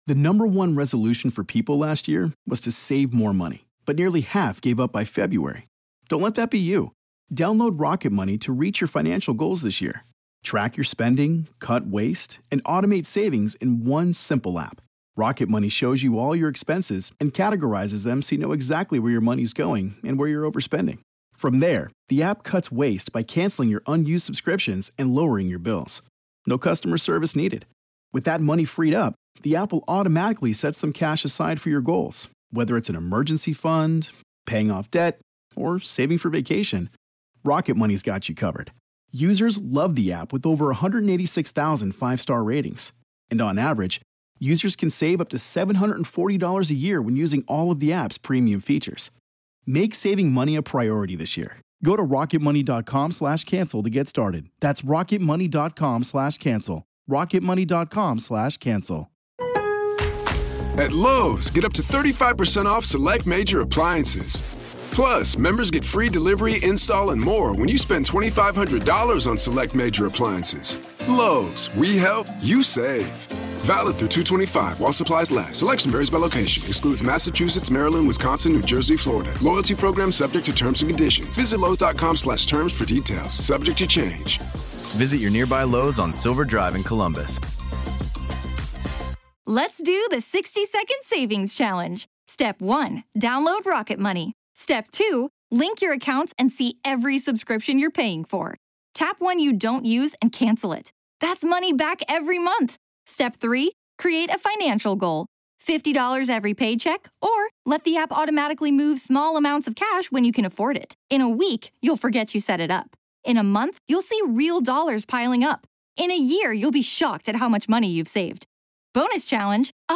A journey that we discuss on The Grave Talks. This is Part Two of our conversation.